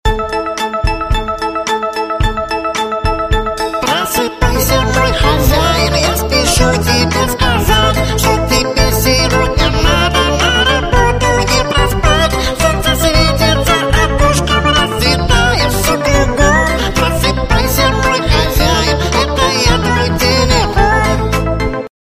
/96kbps) Описание: Класная мелодия на звонок будильника.